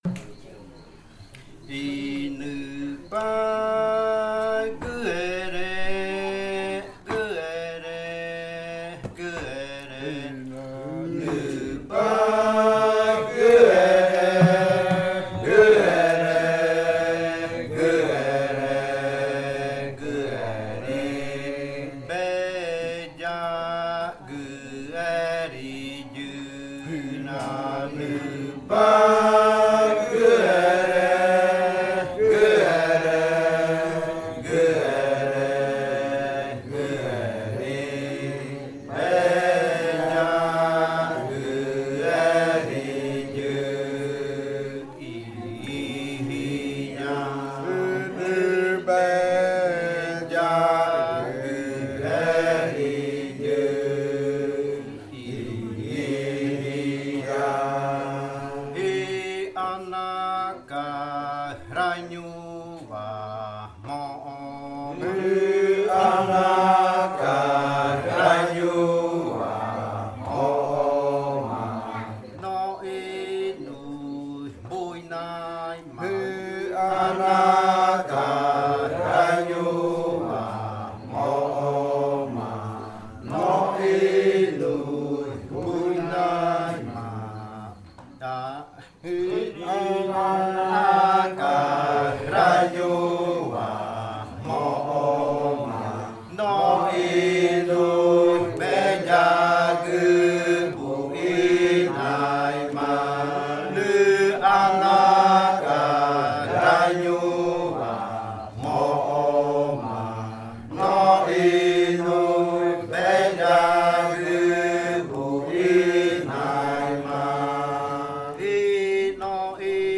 Canto del ritual de tablón (yadiko) del pueblo murui.